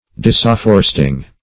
Disafforest \Dis`af*for"est\, v. t. [imp. & p. p.